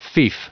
Prononciation du mot fief en anglais (fichier audio)
Prononciation du mot : fief